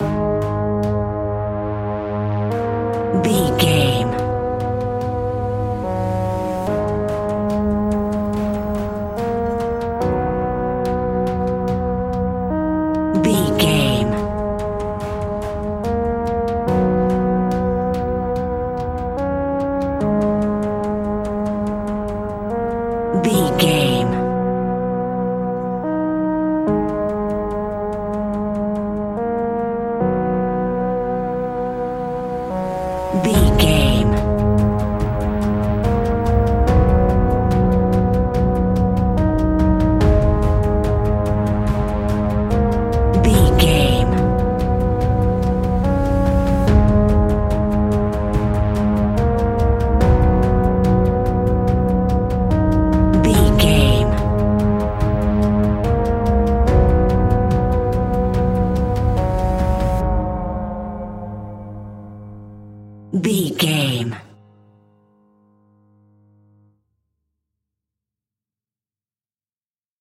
Aeolian/Minor
A♭
ominous
dark
haunting
eerie
piano
synthesizer
horror music
Horror Pads